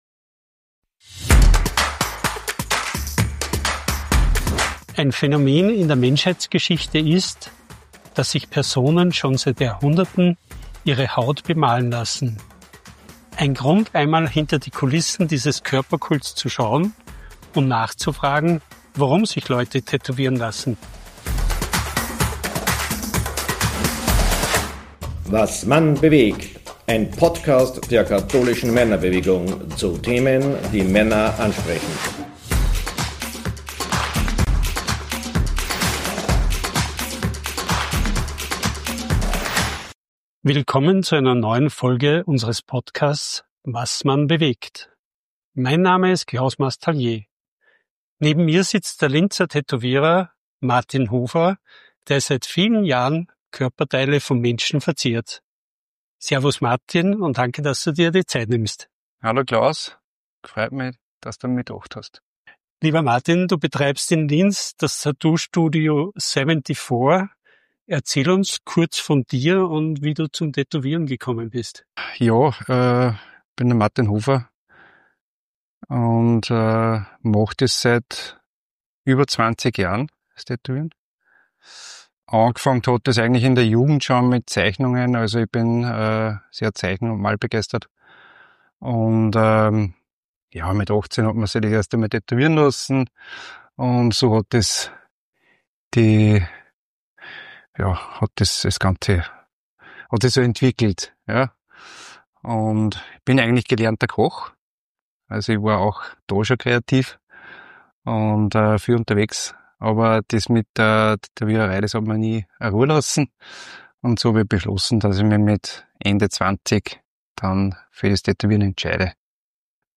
im Gespräch mit Tattoo-Künstler